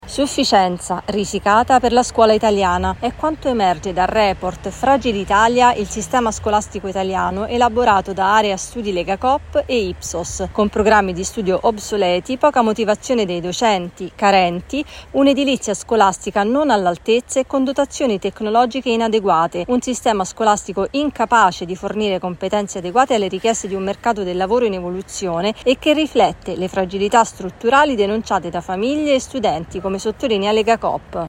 Gli italiani danno una sufficienza molto risicata alla scuola pubblica, è quanto emerge dal report FragilItalia di Ipsos e Area Studi Legacoop. Il servizio